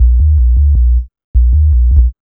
1808L B-LOOP.wav